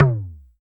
LOGTOM MD M.wav